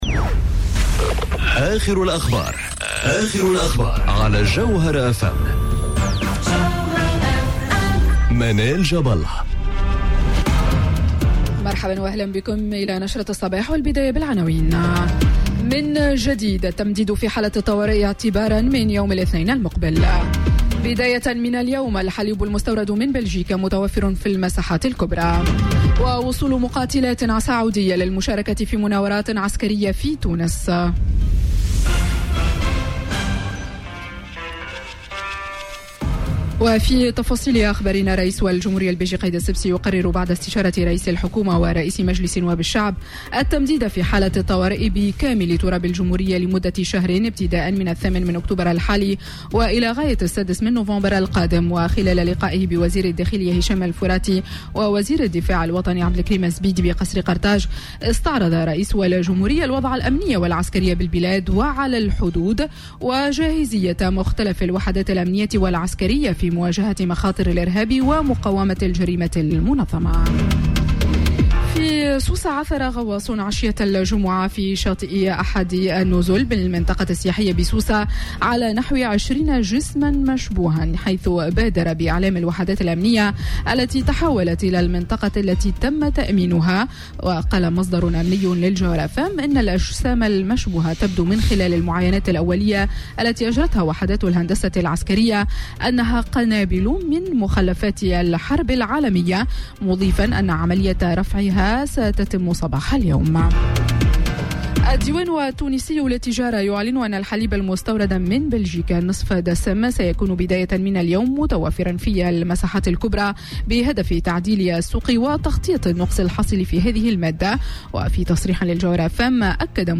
نشرة أخبار السابعة صباحا ليوم السبت 06 أكتوبر 2018